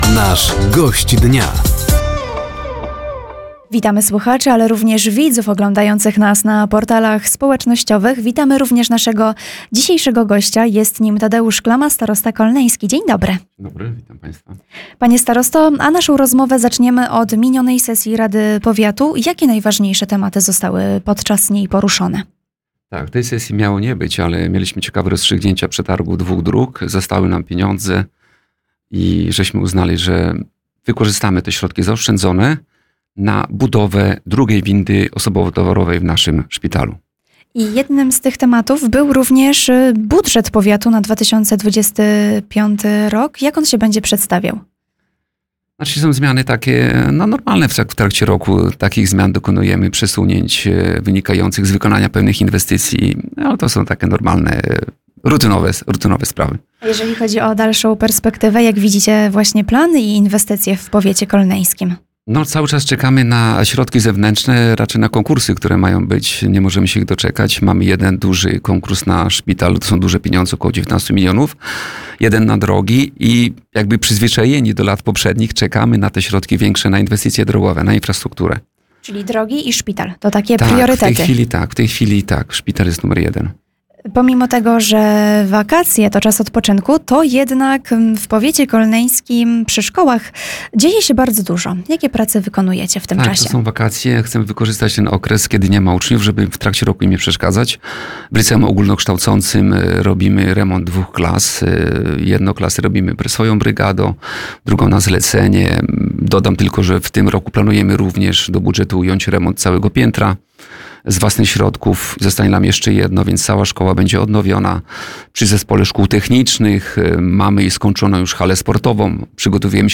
Bieżące inwestycje oraz miniona sesja rady powiatu – to główne tematy rozmowy podczas audycji ,,Gość Dnia”. Studio Radia Nadzieja odwiedził Tadeusz Klama, starosta kolneński.